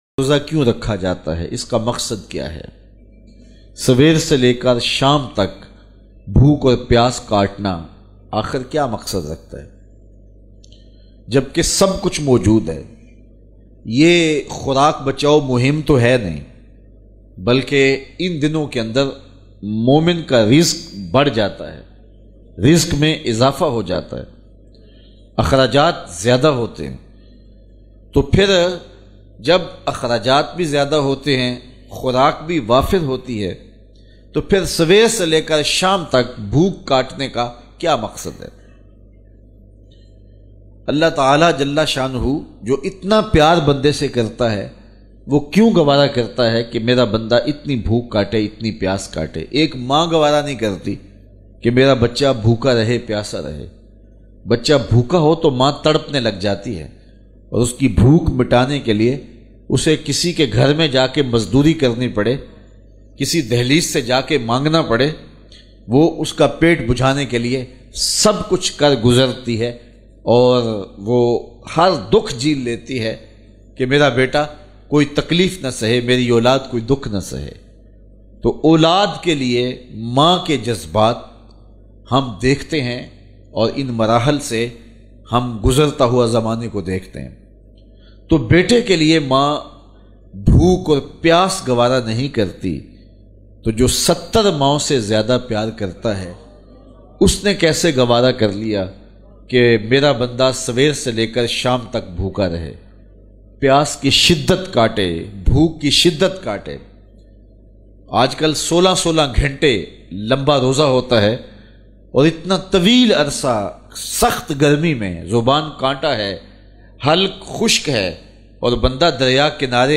Ramzan ka maqsad very nice short bayan
Ramzan ka maqsad very nice short bayan.mp3